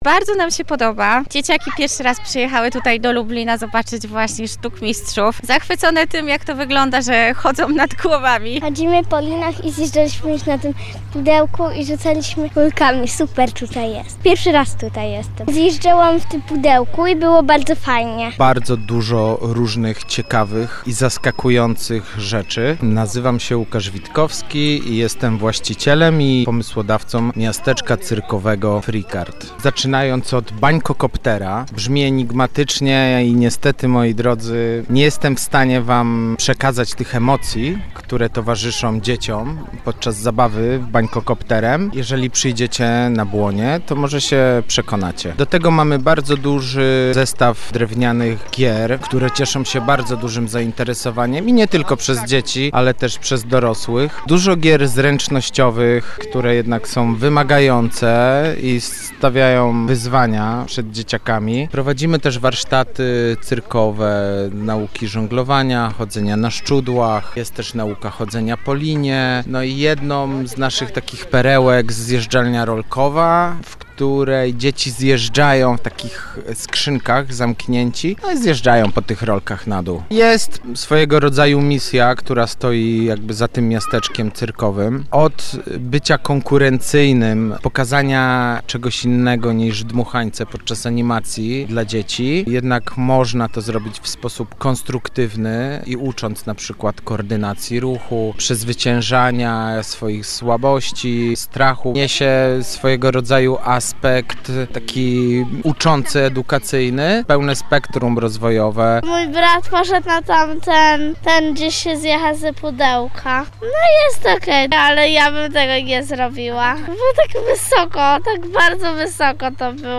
– Bardzo nam się podoba. Dzieciaki pierwszy raz przyjechały do Lublina zobaczyć właśnie sztukmistrzów – mówi jedna z mam spotkanych w Miasteczku Cyrkowym.